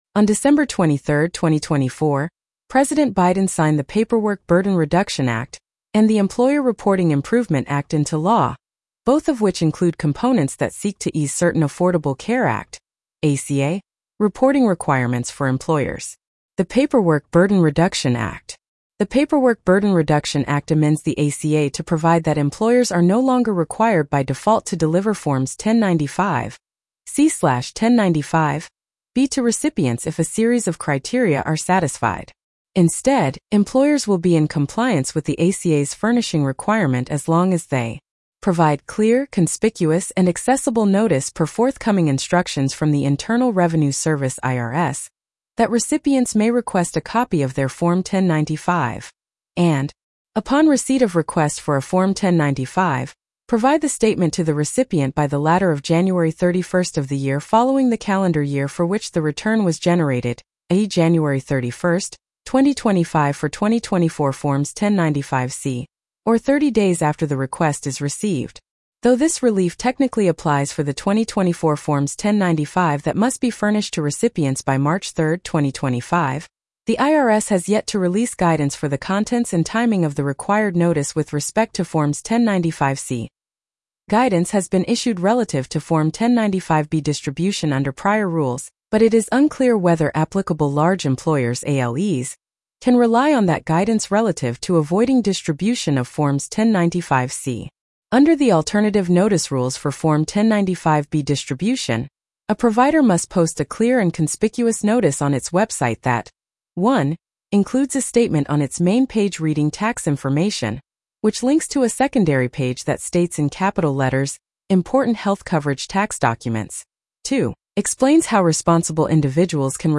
ACA Employer Reporting Relief Issued, Additional Guidance Needed Blog Narration.mp3